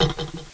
openlock.wav